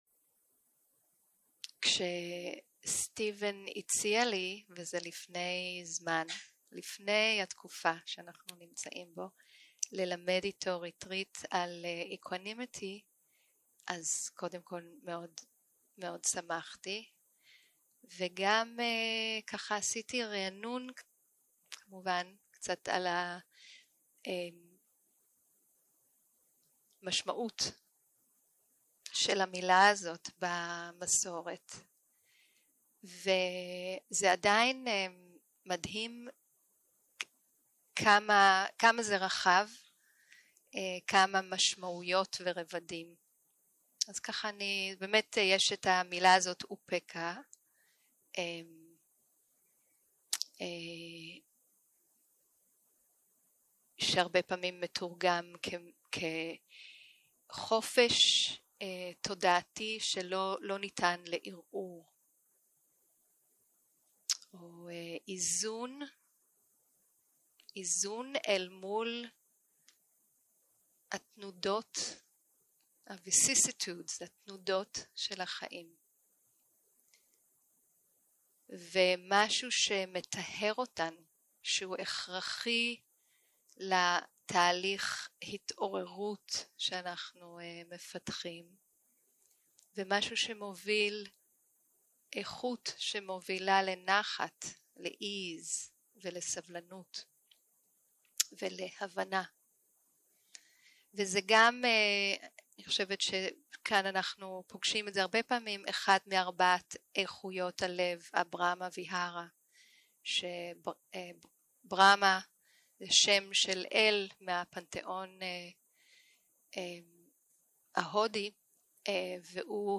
יום 2 - הקלטה 3 - ערב - שיחת דהרמה - איזון מול רוחות החיים
סוג ההקלטה: שיחות דהרמה